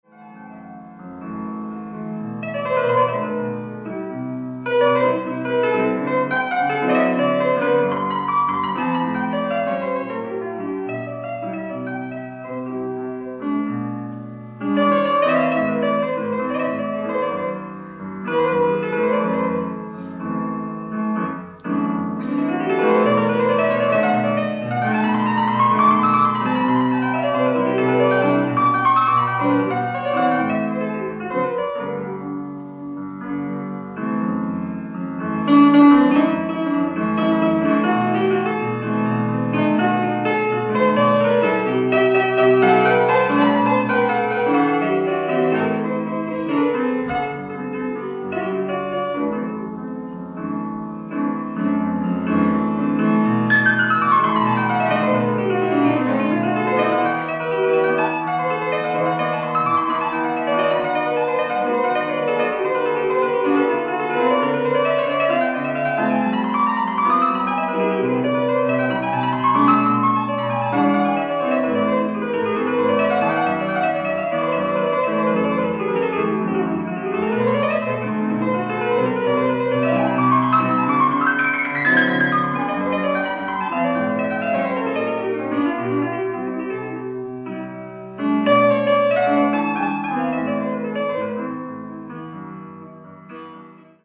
ライブ・アット・シャンゼリゼ劇場、パリ、フランス
※試聴用に実際より音質を落としています。